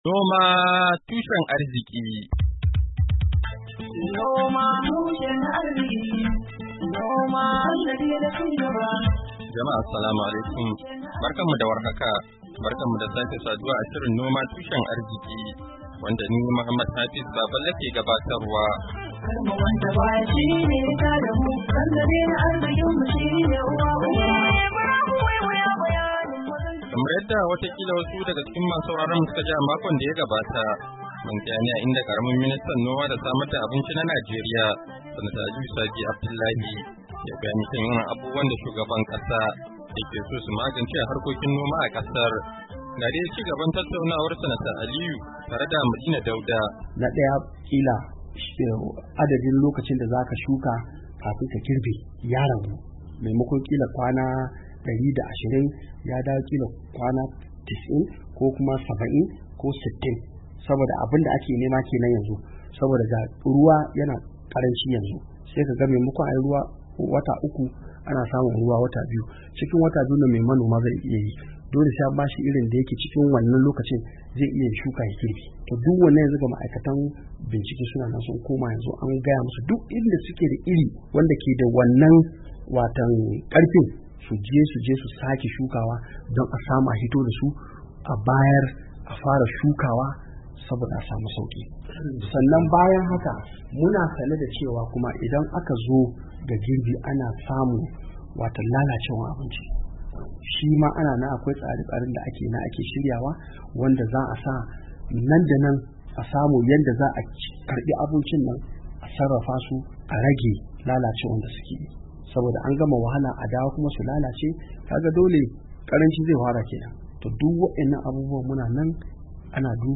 Shirin Noma Tushen Arzki na wannan makon, zai kawo mu ku ci gaban tattauna wa da karamin ministan noma da samar da abinci na Najeriya, Sanata Aliyu Sabi Abdullahi kan batun irin matakan da gwamnatin Najeriya take dauka don inganta samar da abinci a fadin kasar, bayan da al'umma su ka gudanar da...